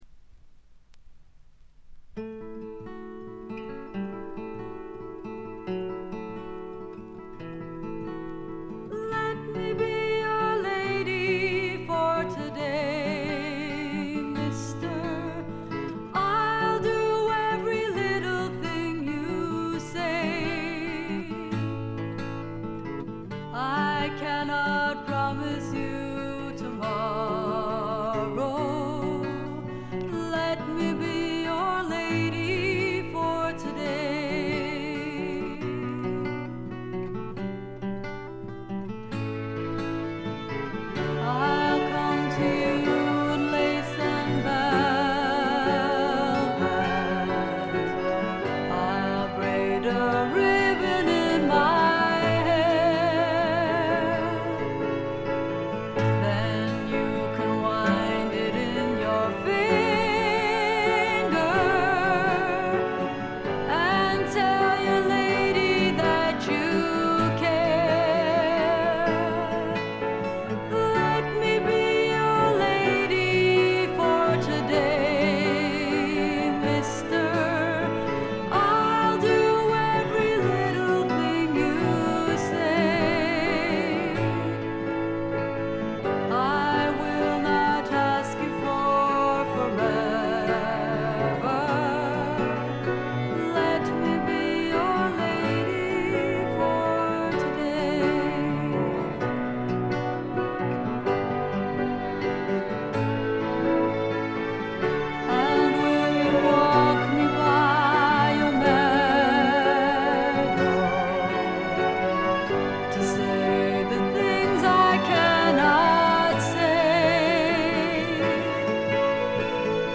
軽微なチリプチ少々。
試聴曲は現品からの取り込み音源です。